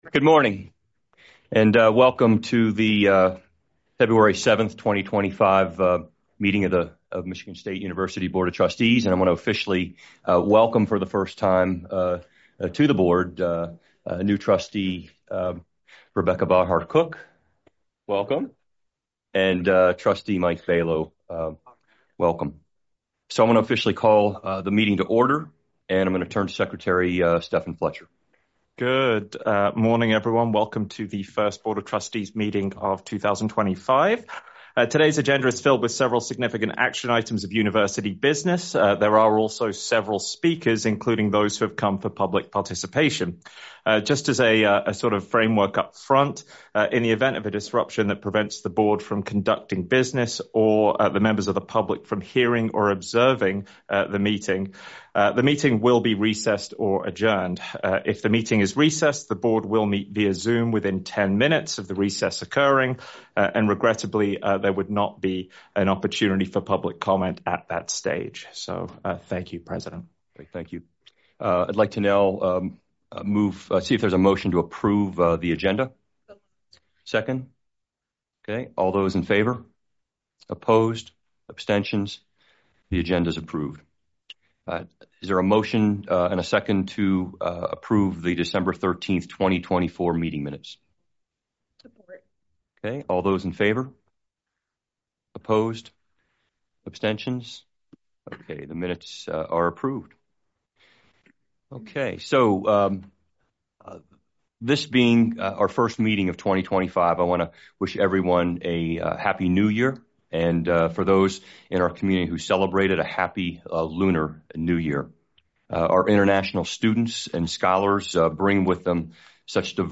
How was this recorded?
Where: Board Room, 401 Hannah Administration Building